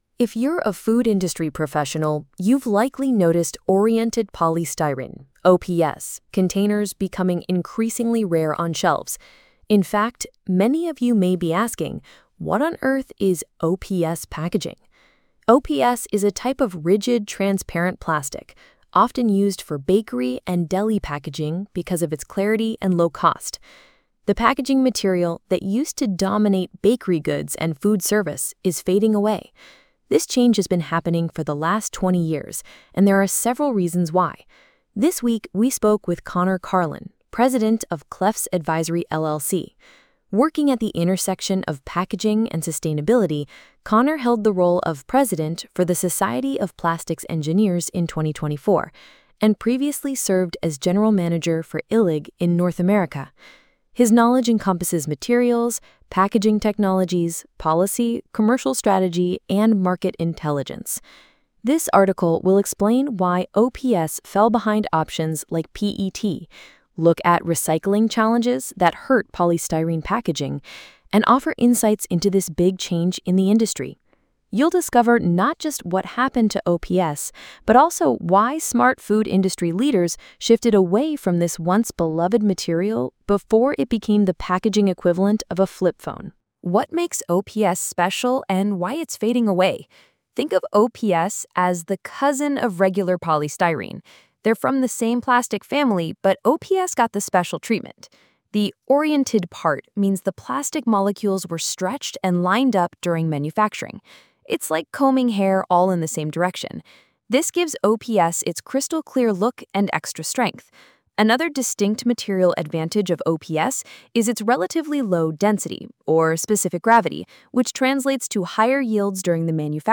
Listen to the audio recording of this blog post below.
Where-is-OPS-Today-and-Have-Food-Industry-Pros-Abandoned-It-Dictation.mp3